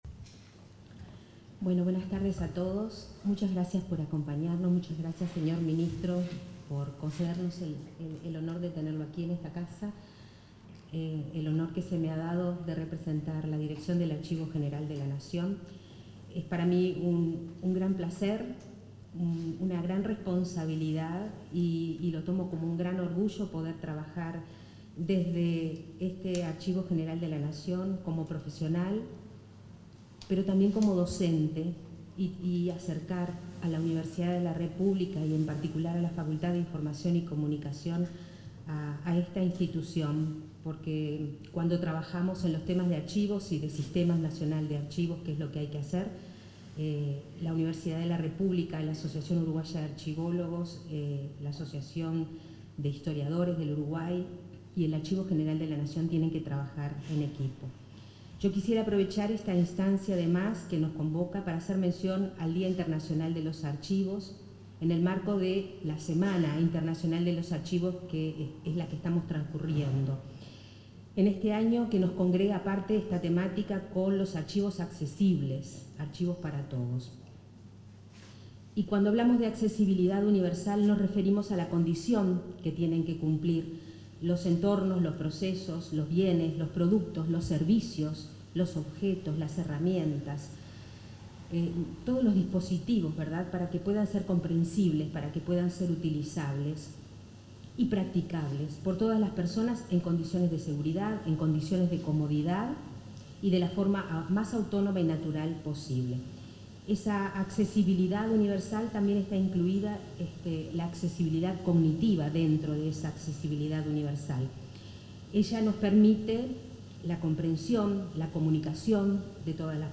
Palabras en la asunción de la directora del Archivo General de la Nación 12/06/2025 Compartir Facebook X Copiar enlace WhatsApp LinkedIn En la ceremonia de presentación de la directora del Archivo General de la Nación (AGN), se expresaron el ministro de Educación y Cultura, José Carlos Mahía, y la nueva titular del AGN, Alejandra Villar.